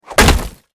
skyrim_mace_hitwall2.mp3